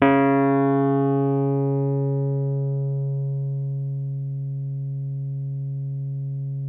RHODES CL07R.wav